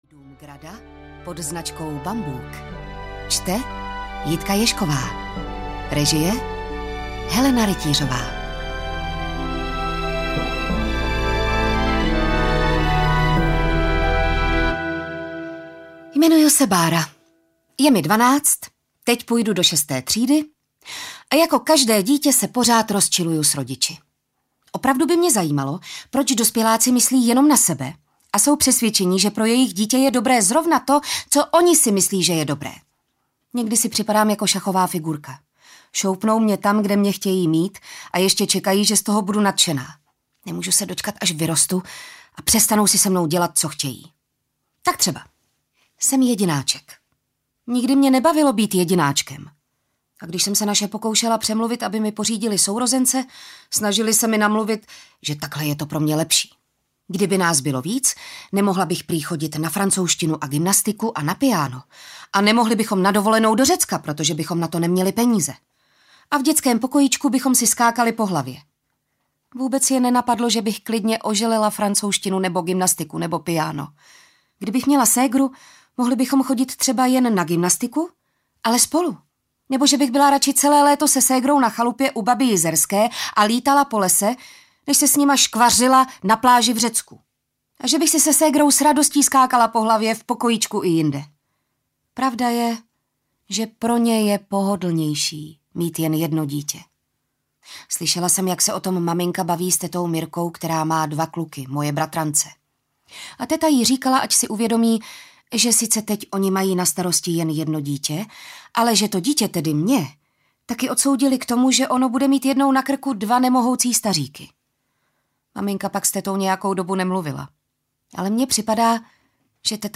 Řím - Pozdvižení v Pompejích audiokniha
Ukázka z knihy